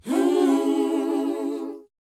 WHOA B BD.wav